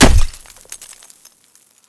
Add sound for clicking the card
frozen2.ogg